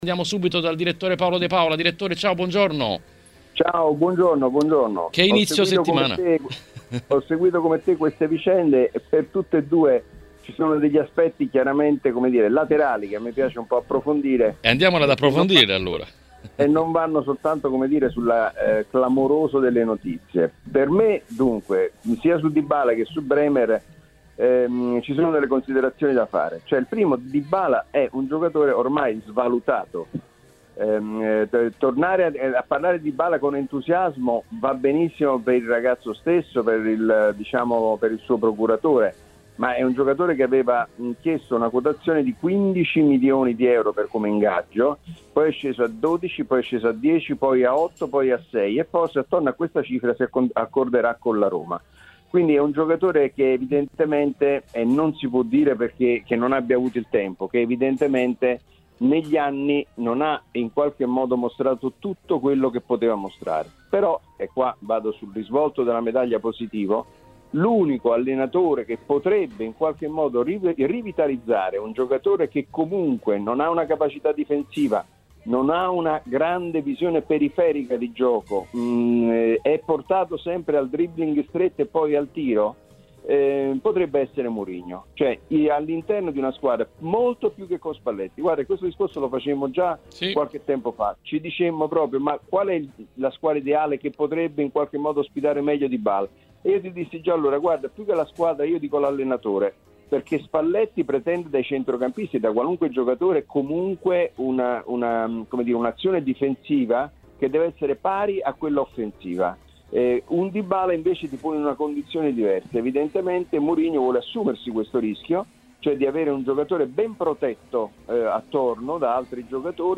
ospite dell’editoriale di TMW Radio.